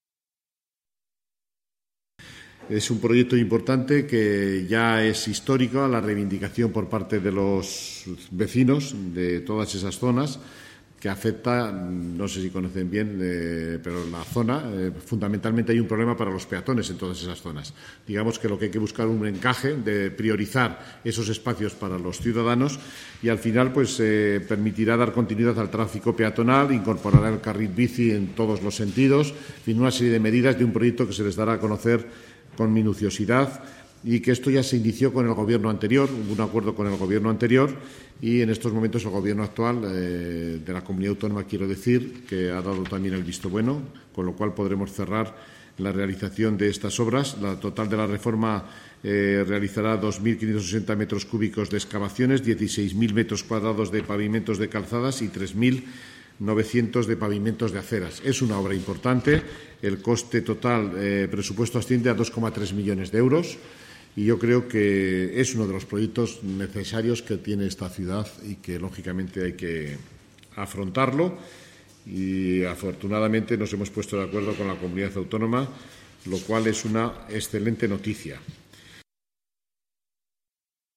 Sobre este asunto, el vicealcalde, Fernando Gimeno, ha manifestado lo siguiente: